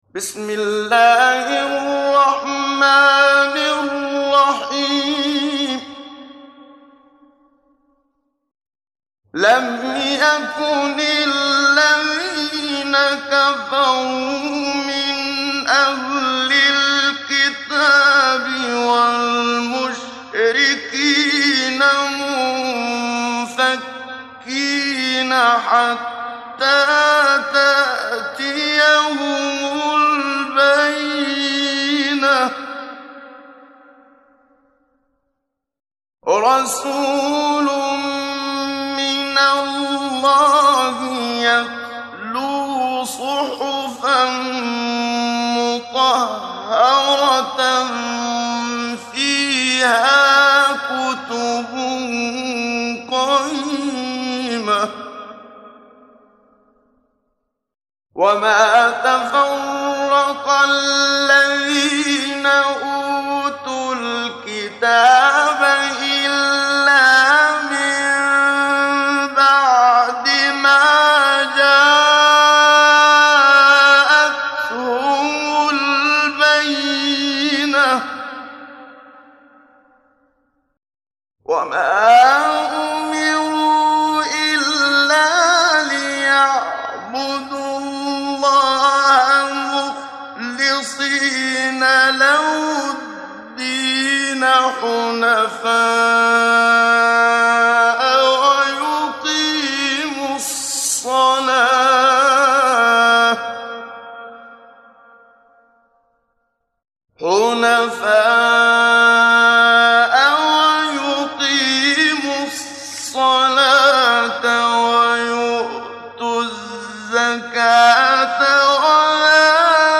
محمد صديق المنشاوي – تجويد